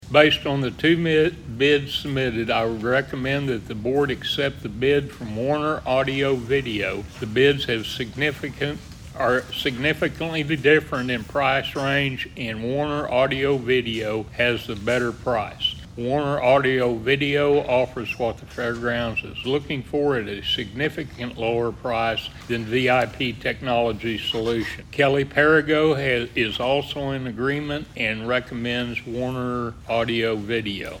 At Monday's Board of Osage County Commissioners meeting, a bid was awarded for a camera security system to be installed at the fairgrounds. There were two bids and District Three Commissioner Charlie Cartwright read a letter to the Board on who they should choose as the vendor.